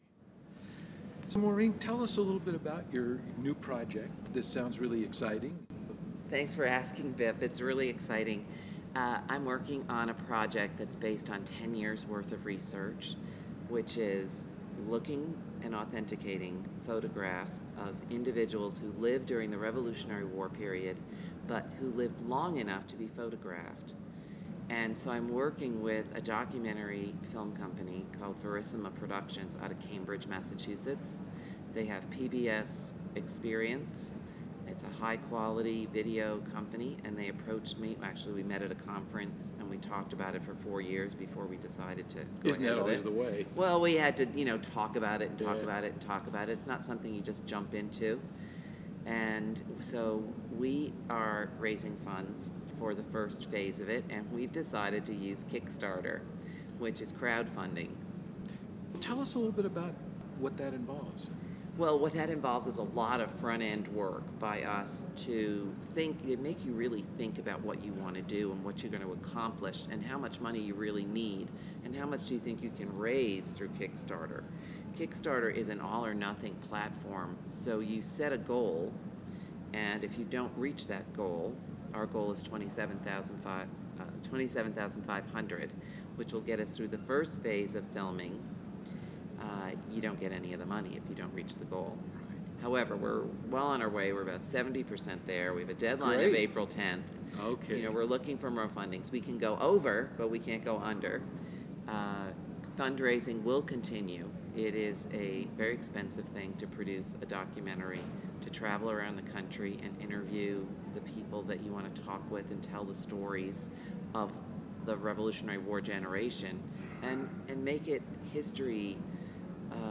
during the recent Roots Tech 2013 Conference in Salt Lake City.
Interview